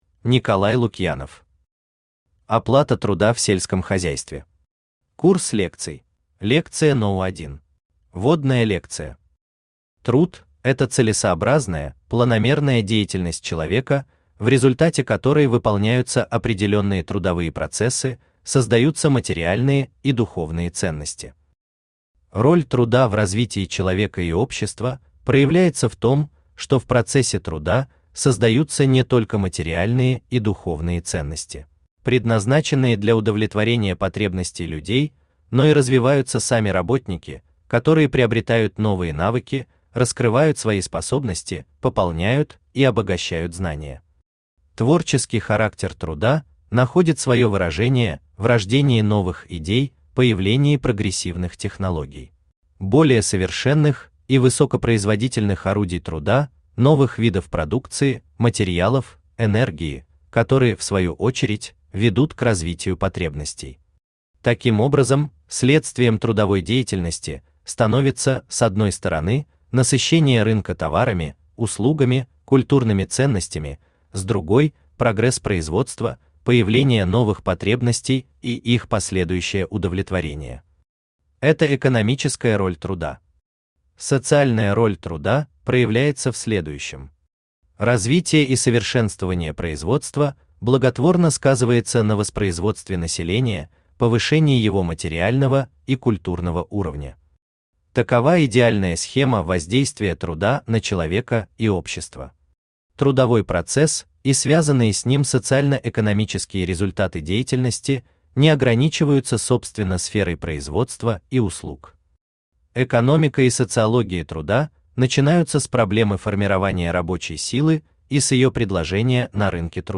Курс лекций Автор Николай Вячеславович Лукьянов Читает аудиокнигу Авточтец ЛитРес.